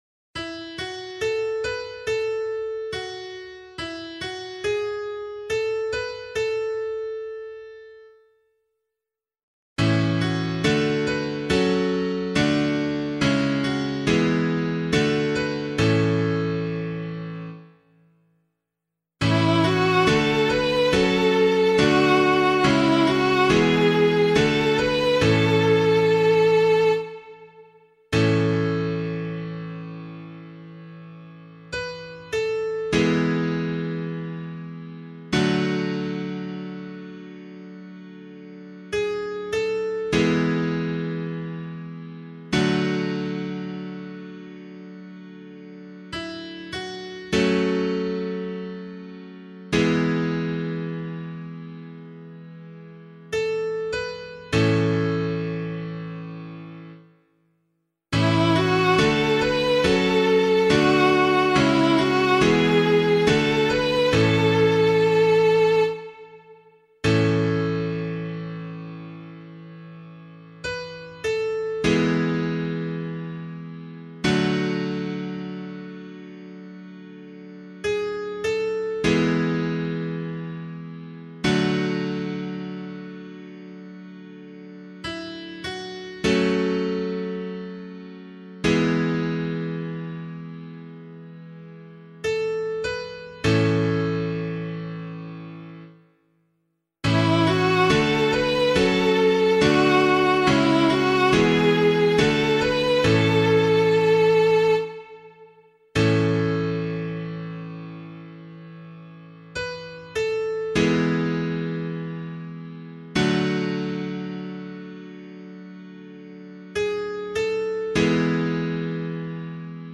068 Christ the King Psalm C [LiturgyShare 8 - Oz] - piano.mp3